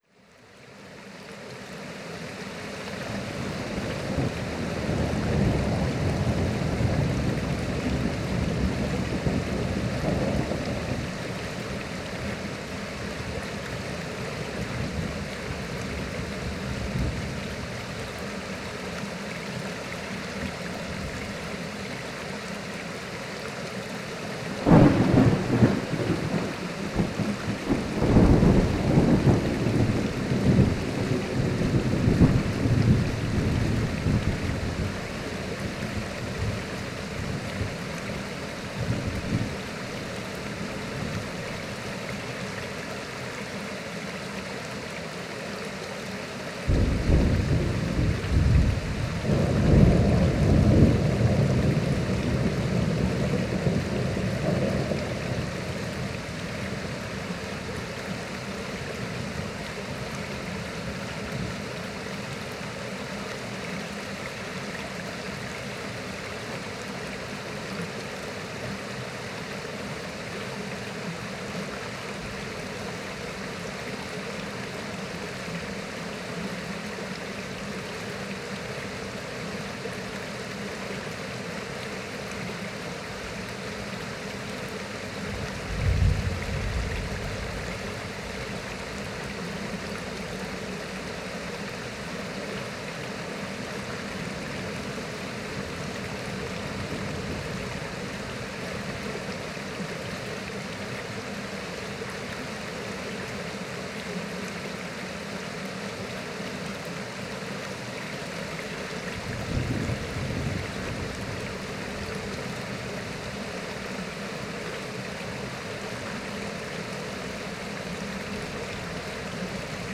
Incoming Storm In The Scottish Highlands With Thunder, Rain, Flowing River And A Distant Waterfall | Our MP3 Collection Of The Natural World Is Free To Download For Personal Use
Rolling Thunder Sounds With Flowing Water And Distant Waterfall | Free MP3 Download
On the day I visited this stunning spot, it was overcast and heavy and the sound of distant thunder was drawing nearer. The soft flowing water from a natural wishing pool trickled by as pure white noise from the waterfall that had carved it gave me an understanding of why this place has been kept hidden and why the Druids chose it as a sacred place of worship.
thunder-running-water-rain-waterfall-10m.mp3